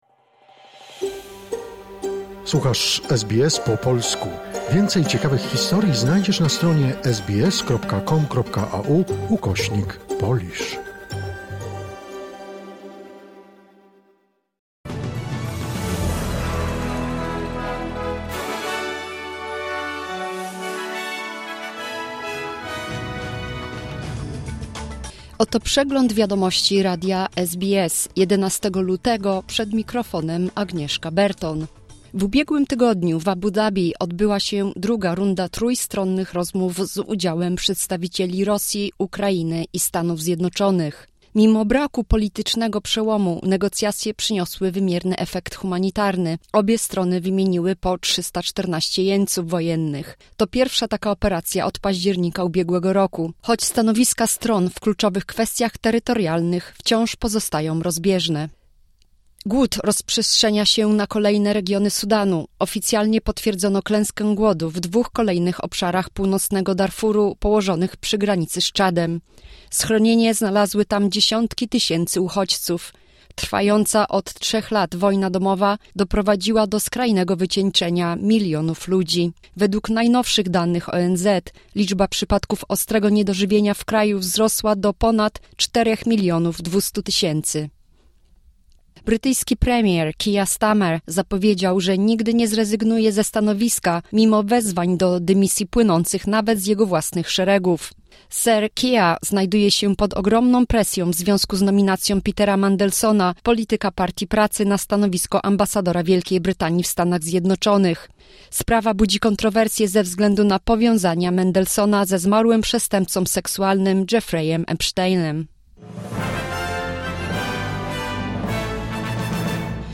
Wiadomości 11 lutego SBS News Flash